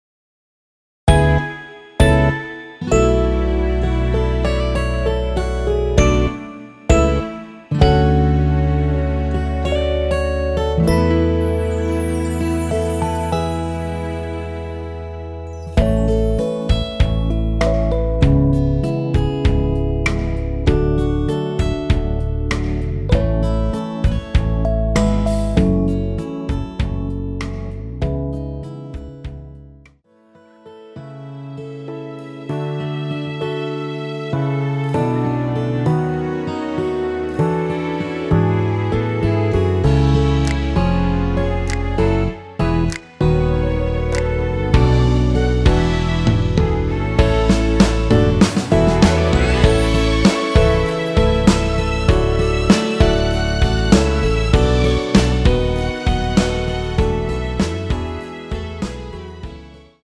원키에서(-5)내린 MR입니다.
앞부분30초, 뒷부분30초씩 편집해서 올려 드리고 있습니다.
중간에 음이 끈어지고 다시 나오는 이유는